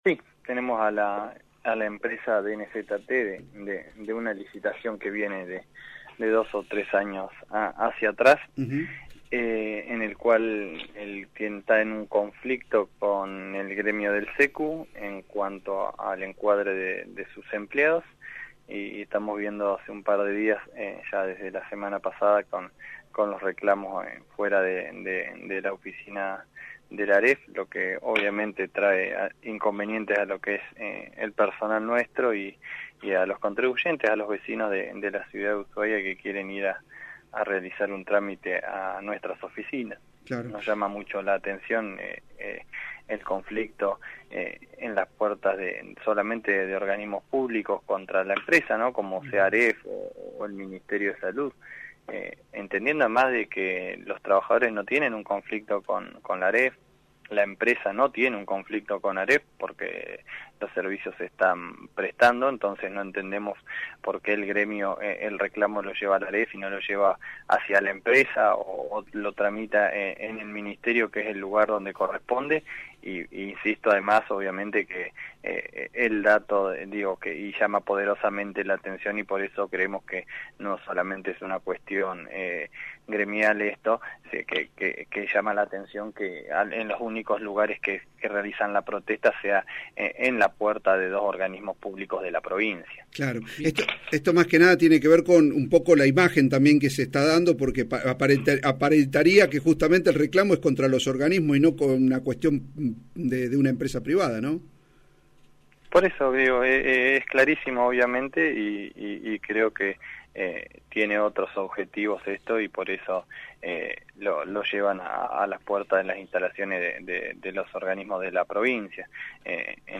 El Director Ejecutivo de la Agencia de Recaudación Fueguina, Oscar Bahamonde, habló en la 103.1 “la Radio Publica Fueguina” donde se refirió al conflicto que vienen manteniendo desde hace semanas los representantes sindicales del Centro Empleados de Comercio de Ushuaia con la empresa DNZT. Confirmó que se hizo la correspondiente denuncia por los inconvenientes que padece tanto el personal como el público que concurre a esa dependencia.